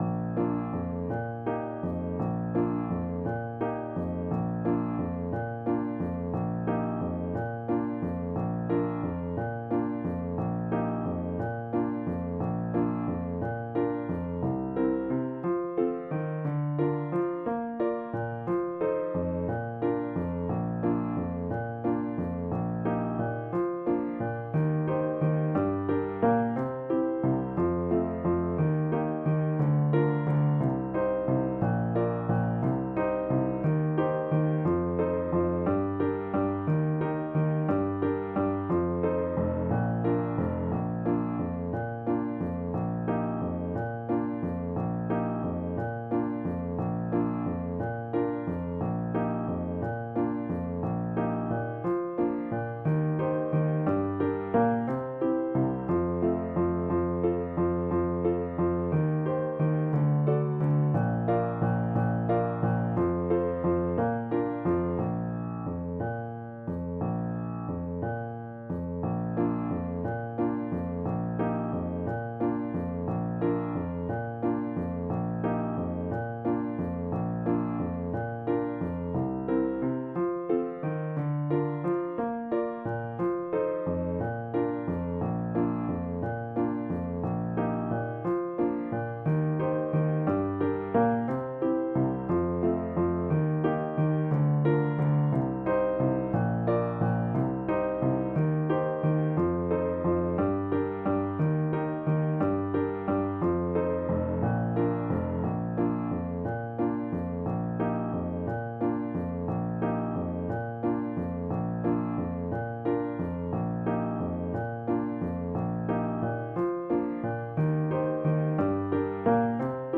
自制karaoke
非消音，听写的。